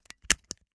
fo_stapler_06_hpx
Papers are stapled together. Paper, Staple